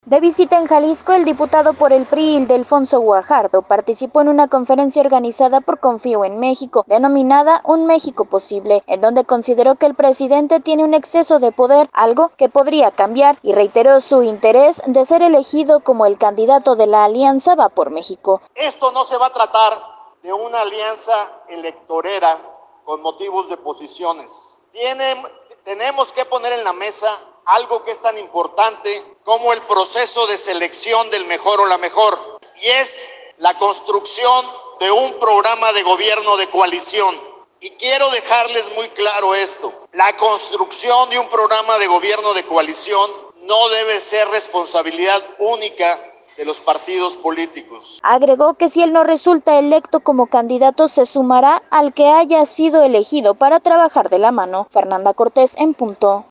Ildelfonso Guajardo impartió una conferencia en Guadalajara
De visita en Jalisco el diputado por el PRI, Ildefonso Guajardo participó en una conferencia organizada por “Confío en México” denominada Un México Posible, donde consideró que el presidente tiene exceso de poder, algo que puede cambiar y reiteró su interés de ser elegido cono el candidato de la “Alianza Va Por México”.